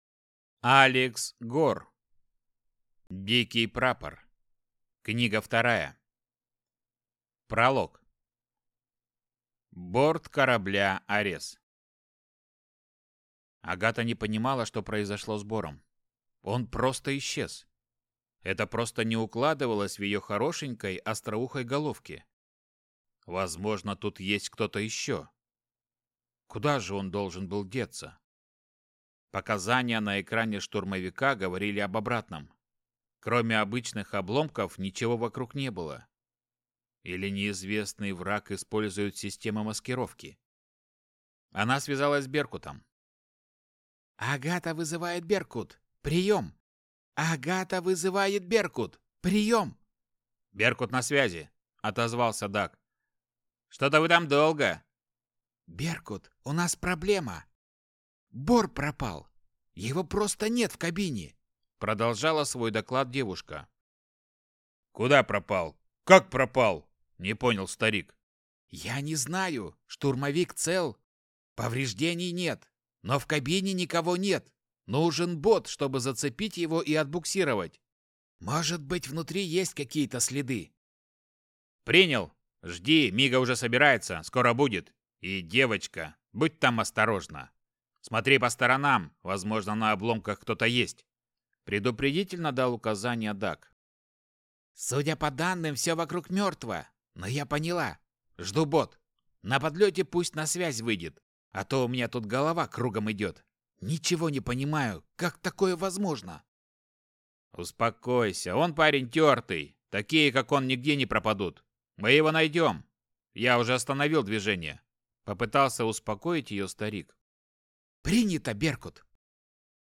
Аудиокнига Дикий прапор. Книга 2 | Библиотека аудиокниг